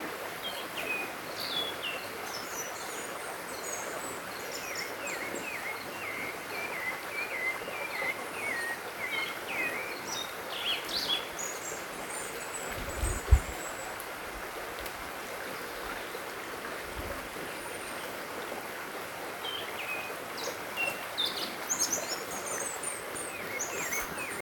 Zorzal Cabeza Negra (Turdus nigriceps)
Nombre en inglés: Andean Slaty Thrush
Localización detallada: Sendero a Cascada los Guindos
Condición: Silvestre
Certeza: Observada, Vocalización Grabada
Zorzal-cabeza-negra.mp3